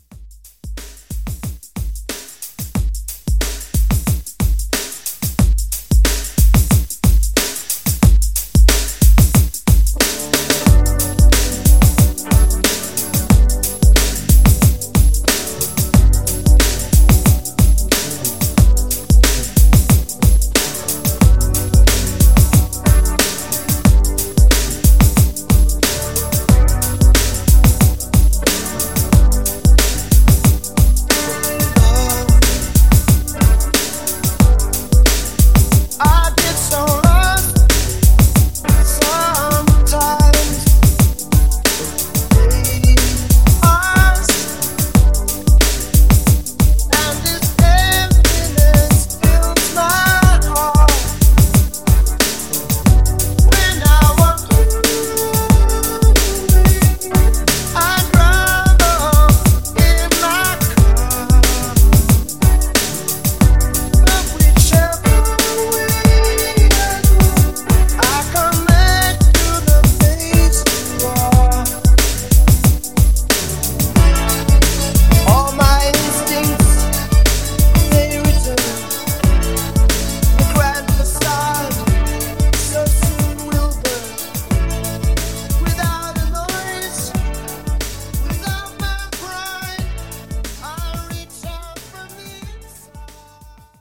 Genres: 80's , RE-DRUM Version: Clean BPM: 90 Time